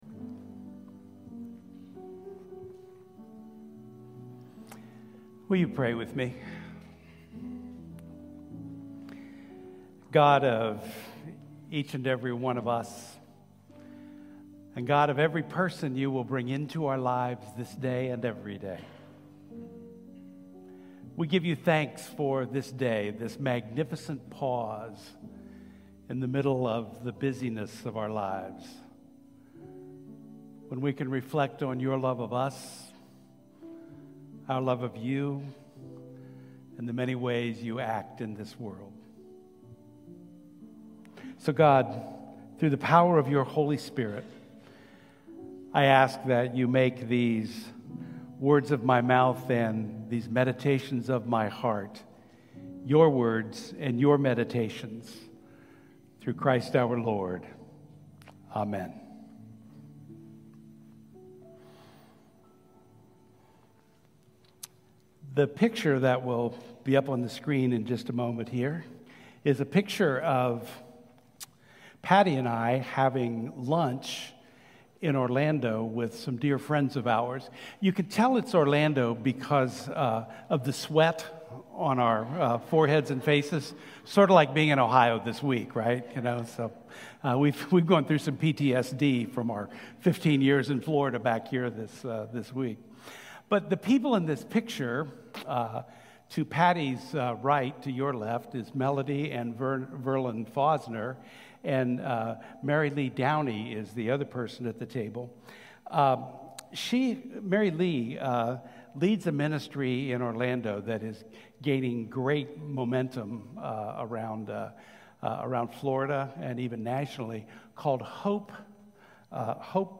11:00 AM Classic Worship Service July 13, 2025 – New Albany United Methodist Church